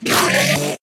Enderman Angry Efecto de Sonido Descargar
Games Soundboard2 views